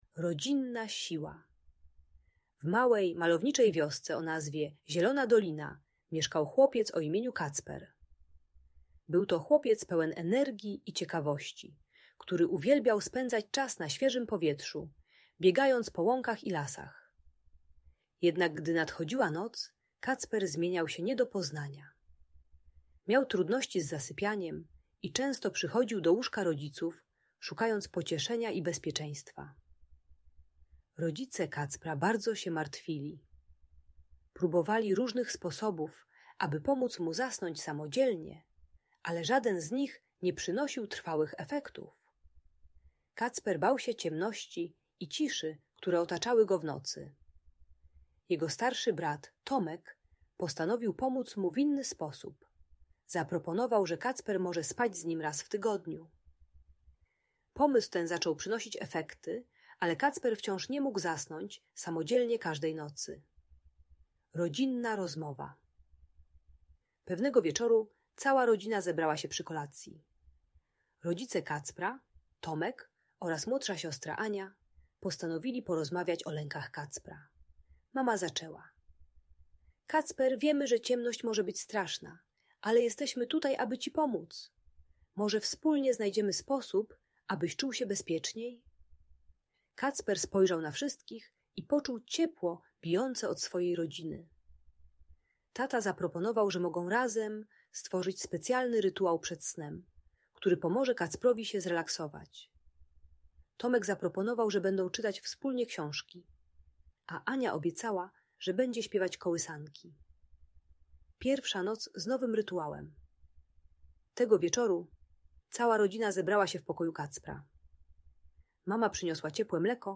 Rodzinna Siła: Historia Kacpra i jego rodziny - Audiobajka